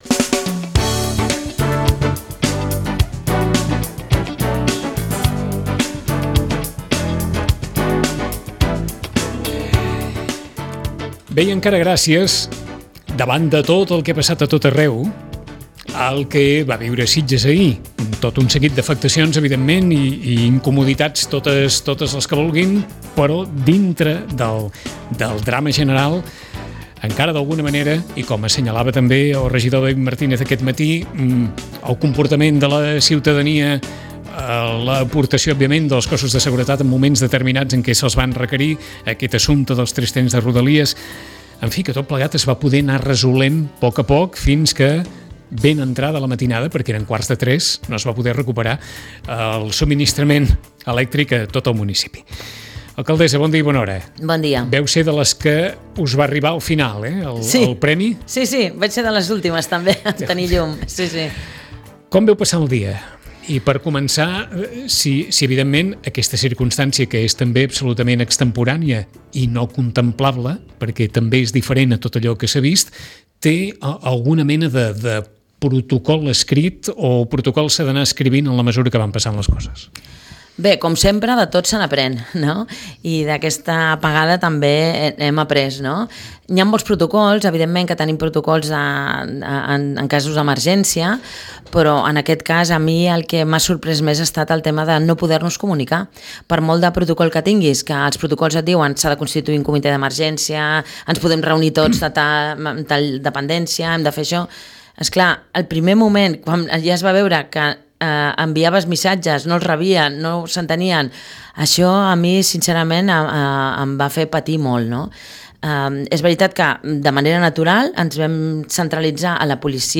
Fem balanç amb l’alcaldessa